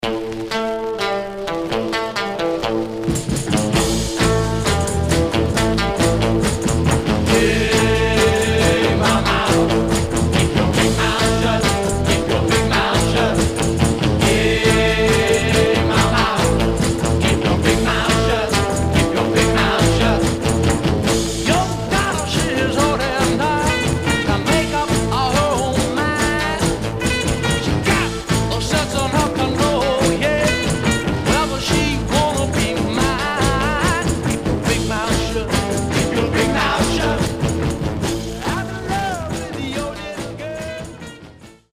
Some surface noise/wear
Mono
Garage, 60's Punk ..........👈🏼 Condition